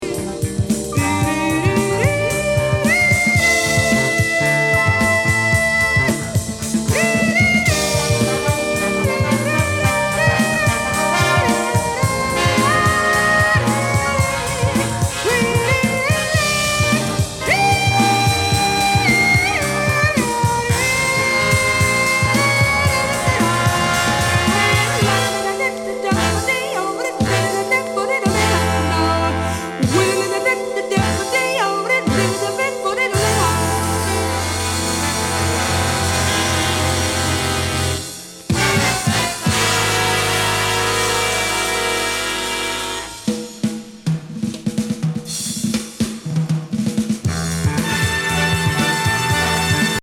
非常にカッコ良いスリリング・グルーヴィ・オーケストラに女性スキャット！